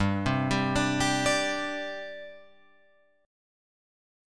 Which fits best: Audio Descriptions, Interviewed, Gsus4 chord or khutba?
Gsus4 chord